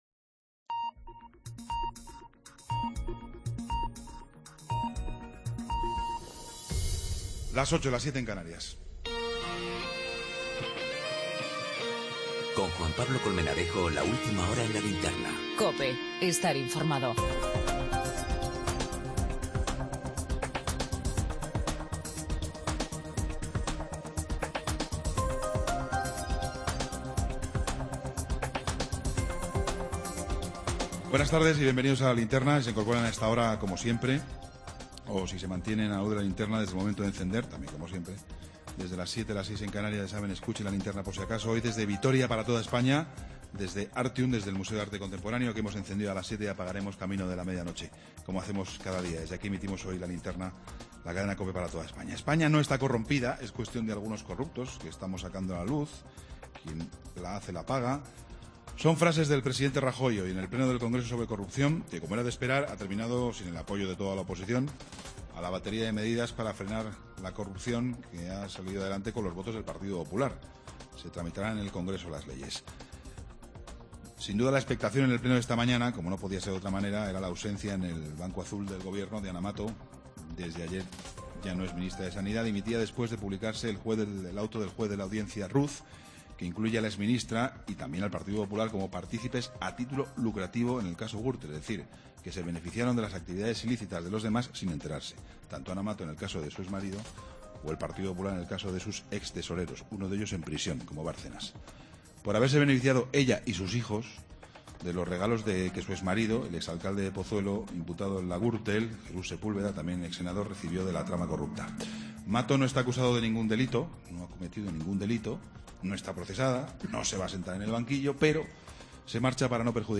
Entrevista al Alcalde de Vitoria, Javier Maroto.